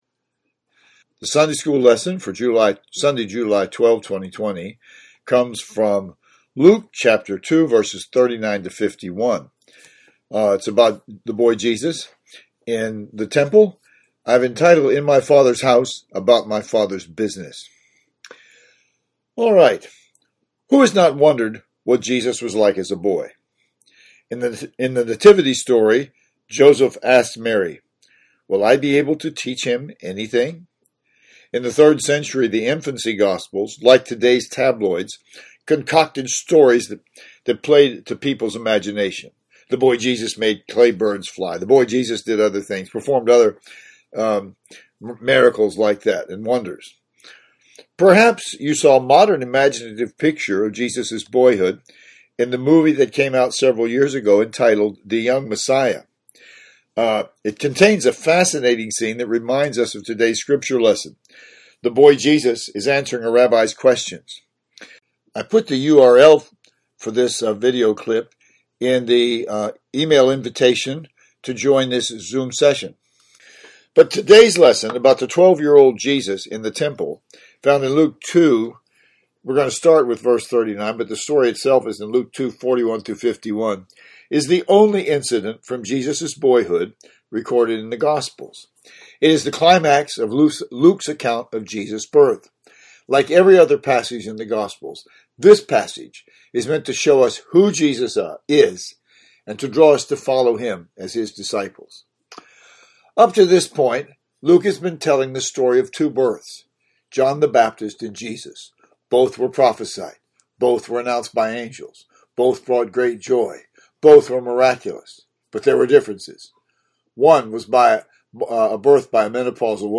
Sunday School.